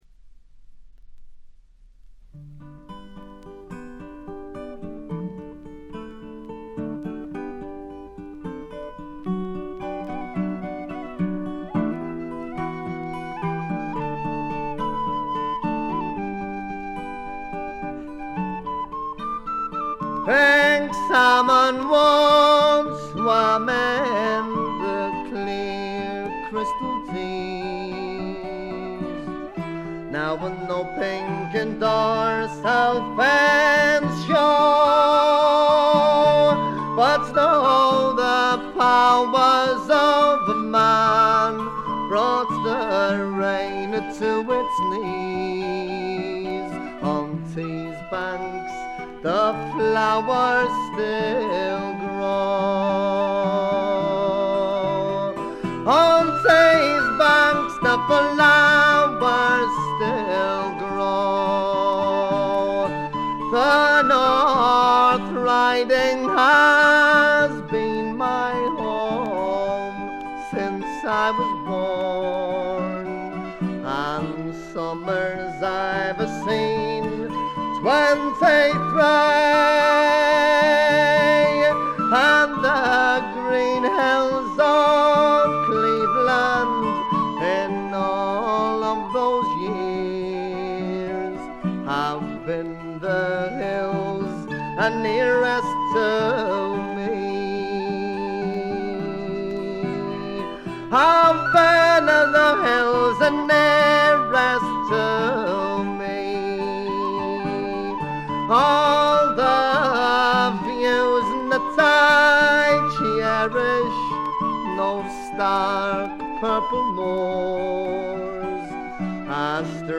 試聴曲は現品からの取り込み音源です。
Guitar ?
Tin Whistle ?